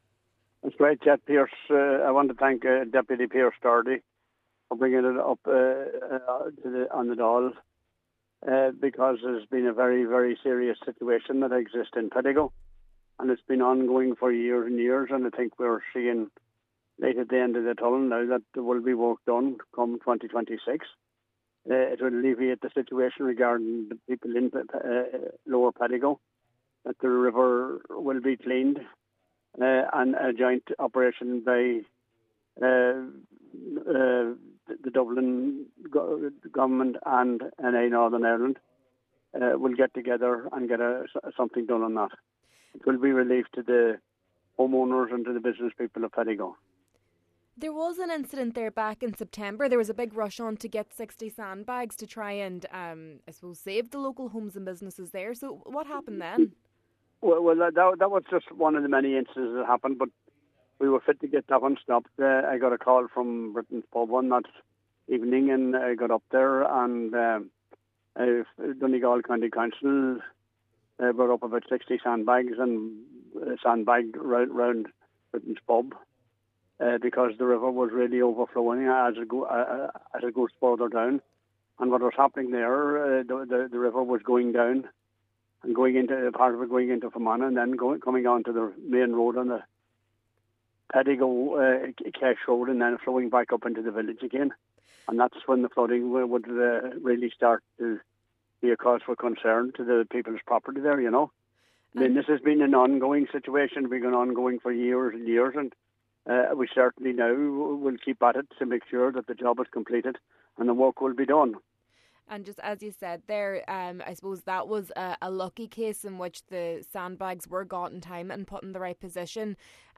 However, Cllr McMahon says that has not always been the case: